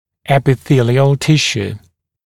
[ˌepɪ’θiːlɪəl ‘tɪʃuː] [-sjuː][ˌэпи’си:лиэл ‘тишу:] [-сйу:]эпителиальная ткань